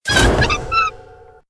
audio: Converted sound effects
CHQ_VP_reeling_backwards.ogg